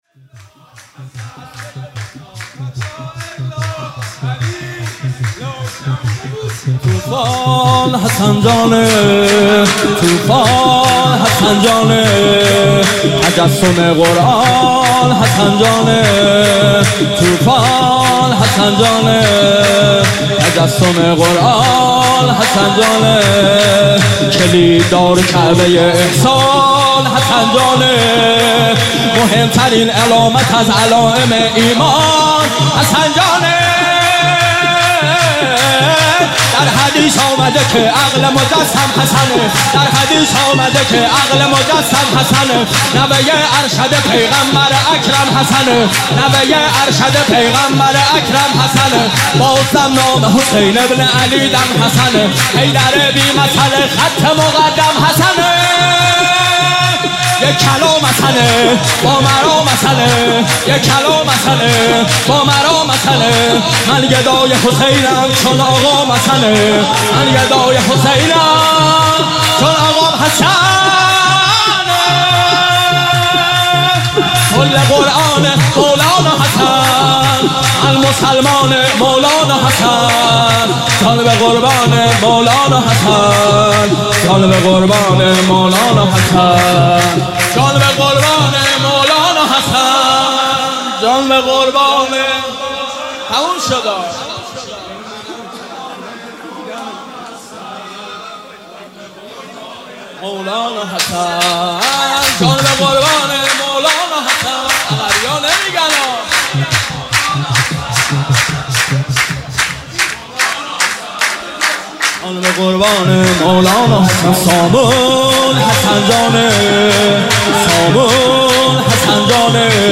عنوان جشن ولادت امام حسن مجتبی علیه السلام – شب پانزدهم ماه مبارک رمضان ۱۳۹۸
سرود-شور